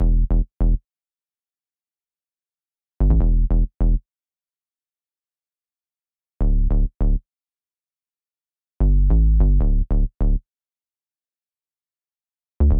Tag: 150 bpm Ambient Loops Bass Loops 2.15 MB wav Key : Unknown